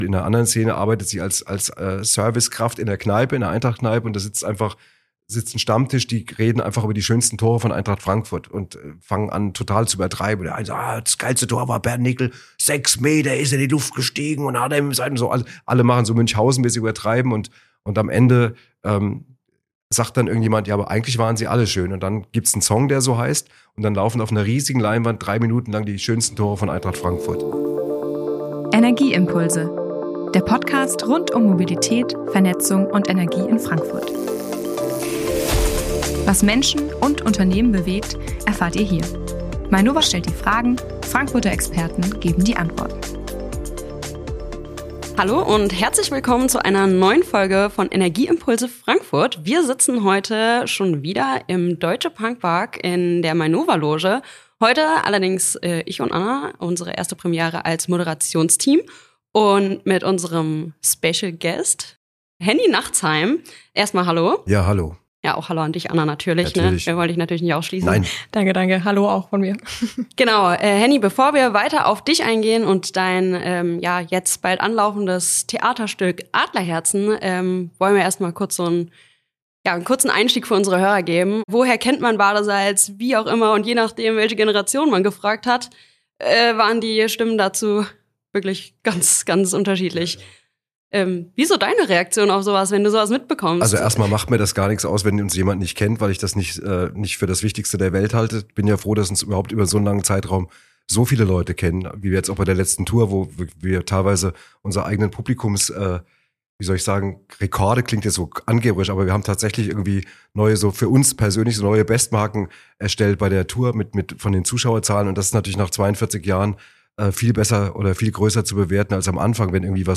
#26 – Eintracht, Badesalz, Adlerherzen: Henni Nachtsheim im Gespräch ~ Energieimpulse Frankfurt Podcast